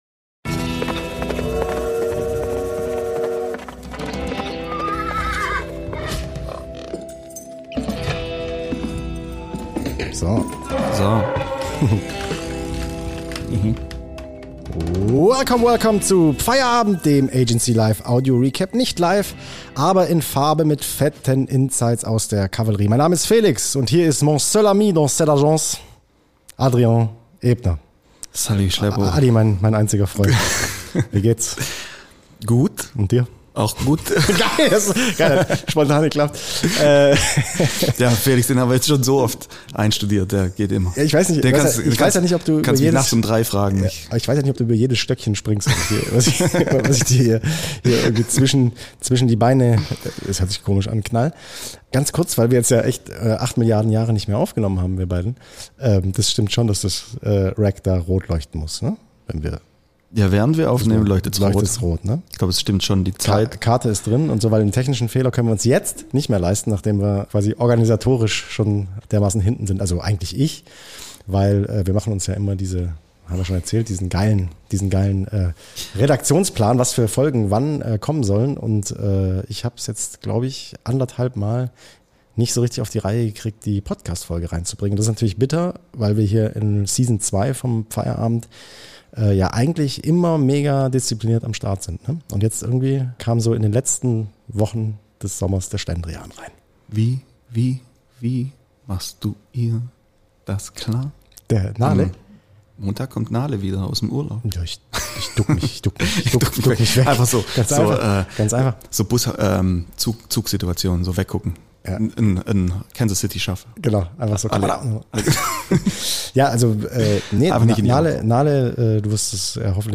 Also wird das Equipment kurzerhand in angenehmere Temperaturen verschoben, die Erfrischungsgetränke geöffnet und schon kann’s losgehen.